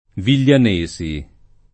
[ vil’l’an %S i ]